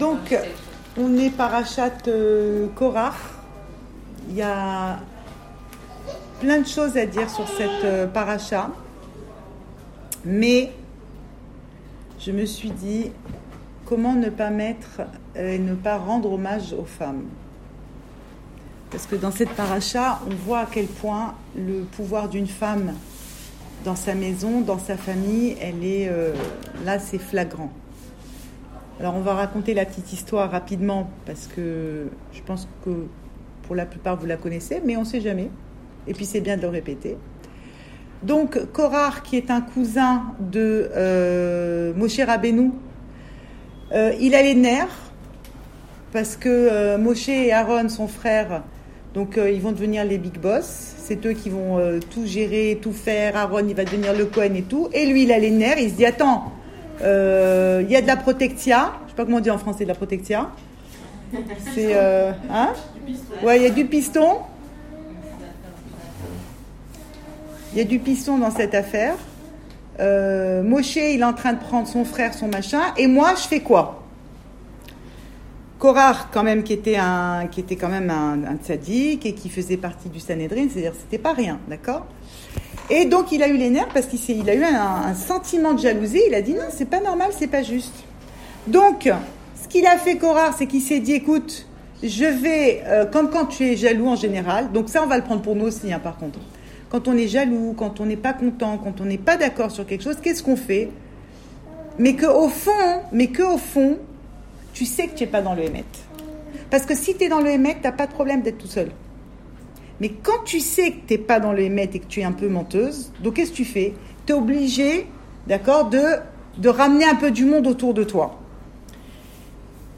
Cours audio
Enregistré à Raanana